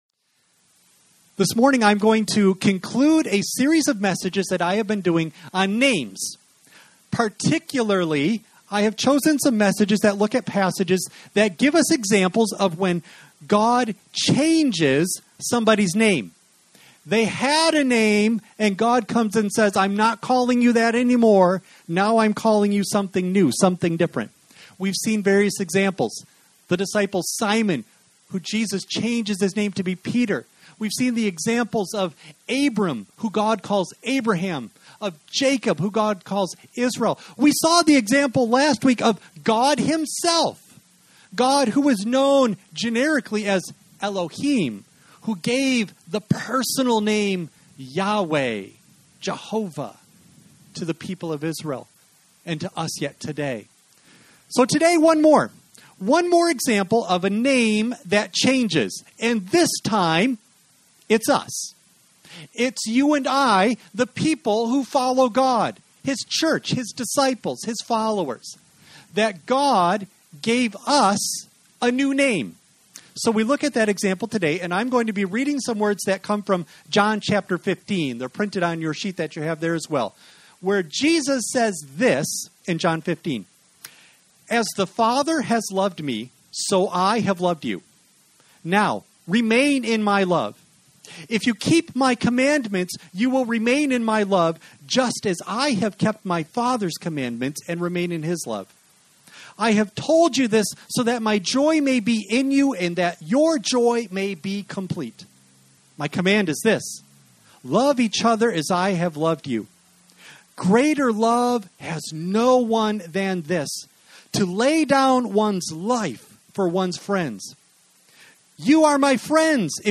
Service Type: Sunday AM
Audio only of message